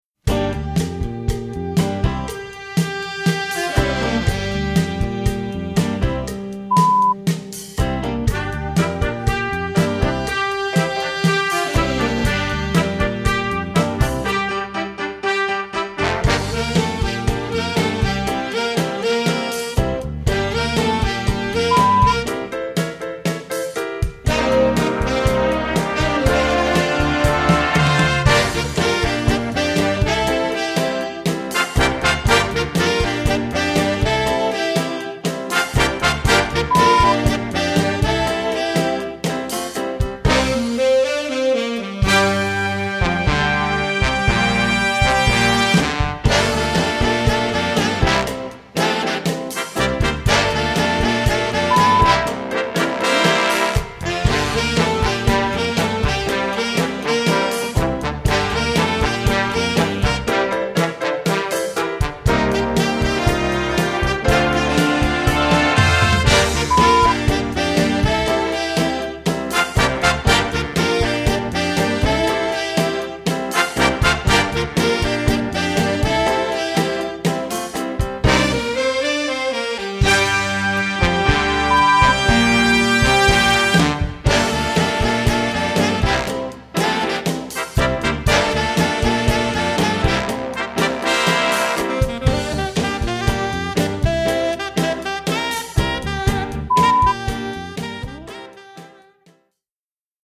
Répertoire pour Jazz band - Jazz Band